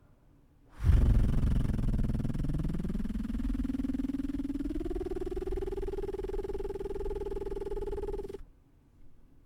2. タングトリルで地声～裏声を連結してみる
音量注意！
このリップバブルをほっぺたを膨らましながらやると参考音源のような状態になります。